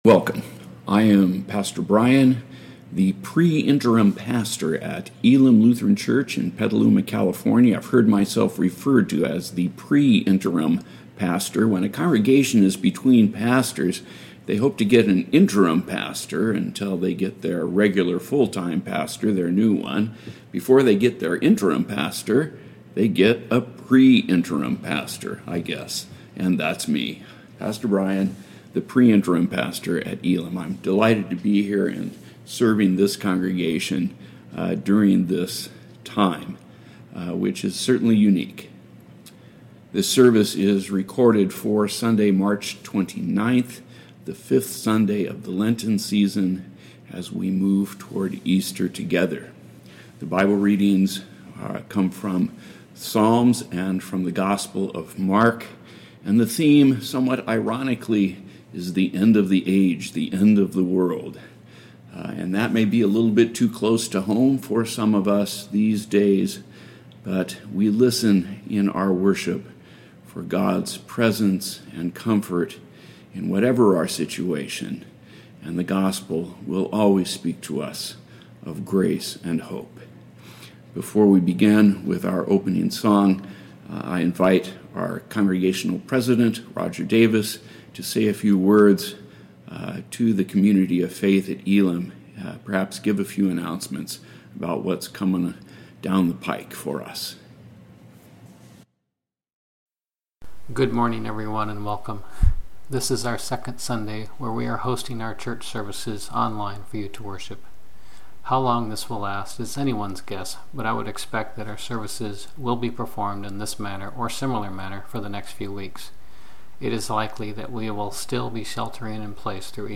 Sermons | Elim Lutheran Church